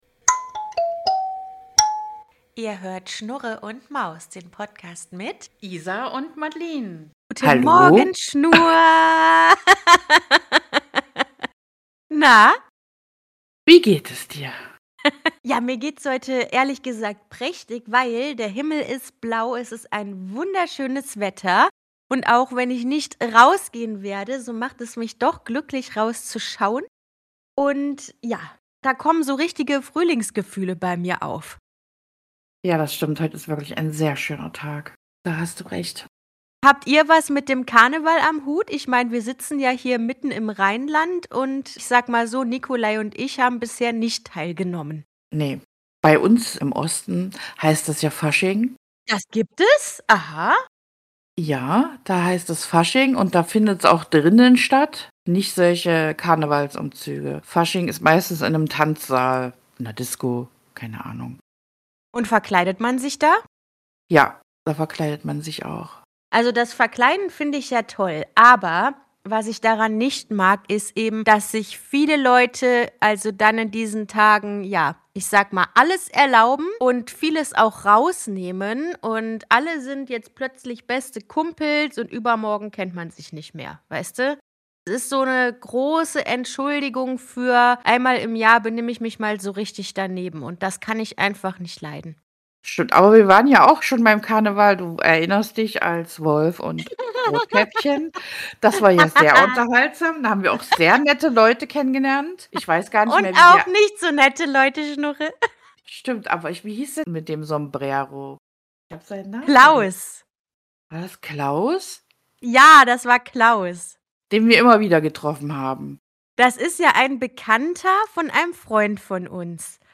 Heute so: Von psychedelischen Trips über gepiepte Stellen bis hin zur Mordlust.